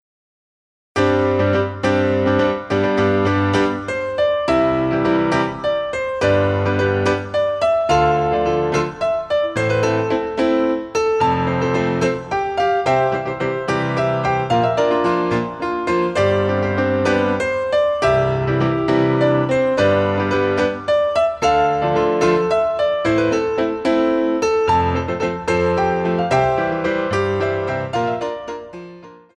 MEDIUM ALLEGRO II